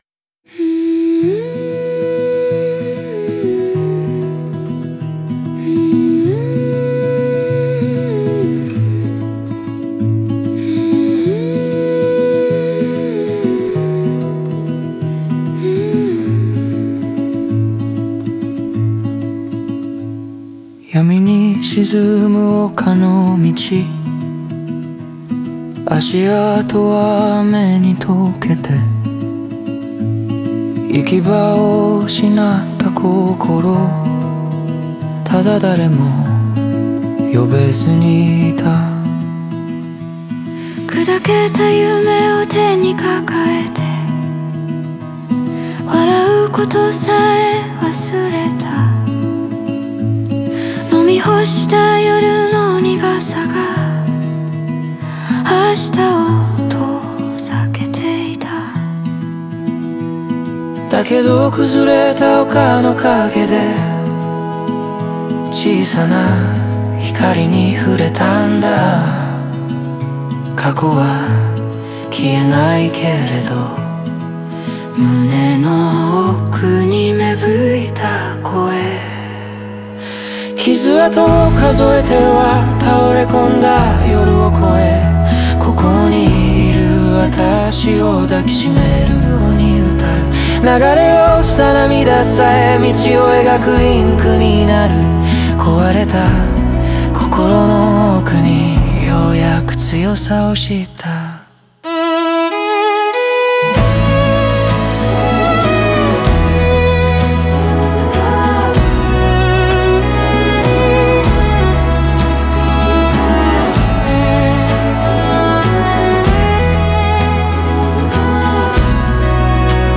オリジナル曲♪
繊細な音像と声の揺らぎが、傷を抱えながらも愛を見つけた心情を鮮やかに浮かび上がらせています。